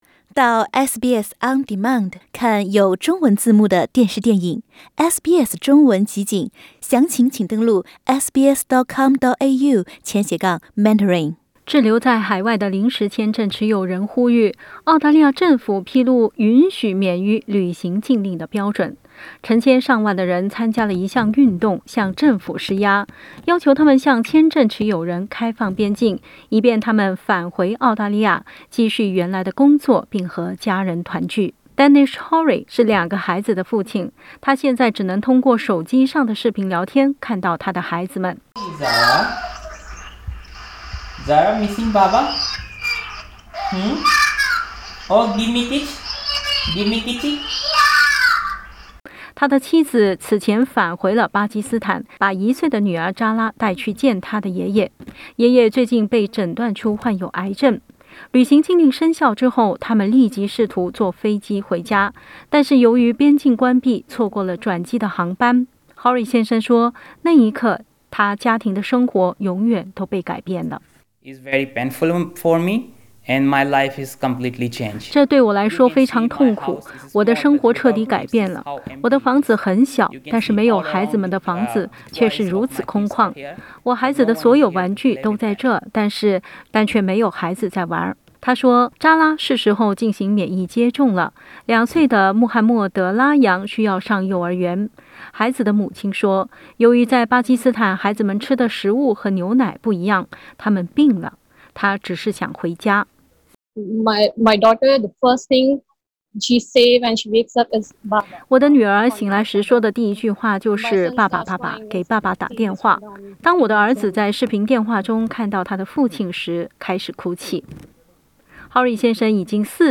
点击图片收听详细报道。